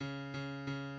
key Bm